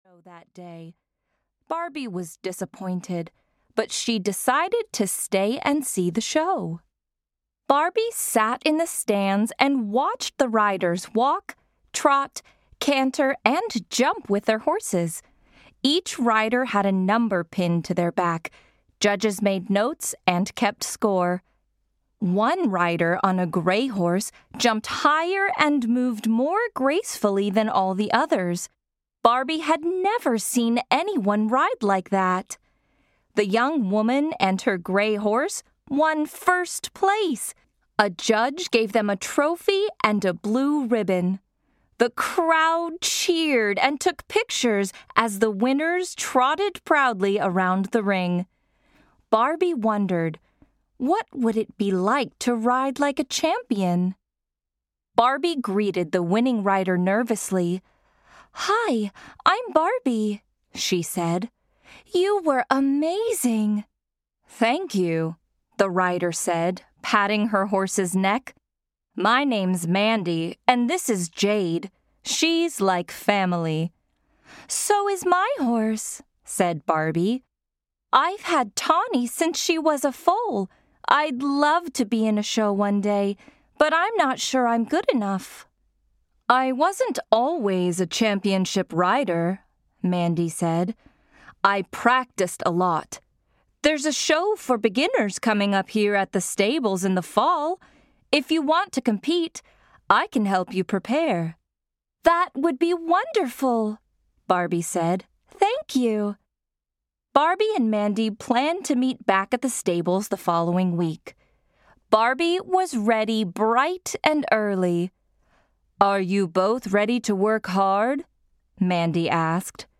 Audio knihaBarbie - You Can Be - Dream Big Collection (EN)
Ukázka z knihy